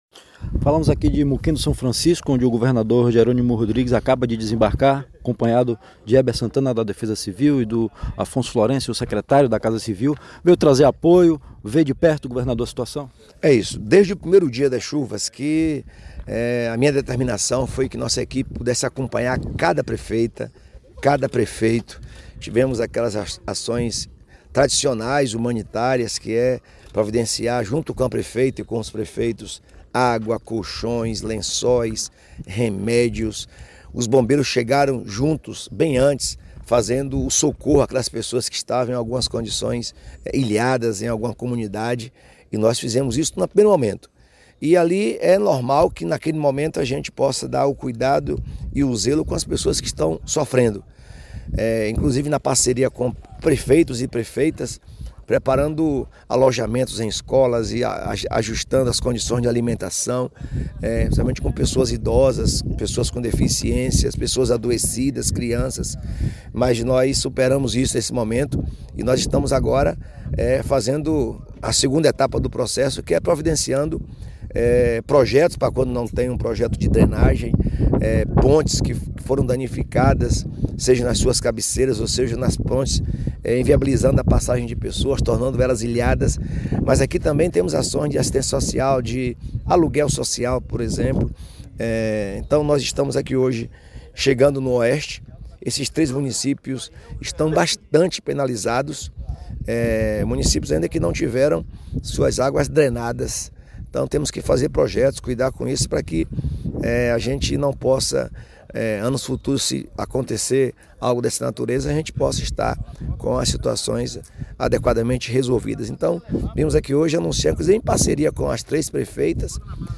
Entrevista com o superintendente da Defesa Civil no Estado, Heber Santana.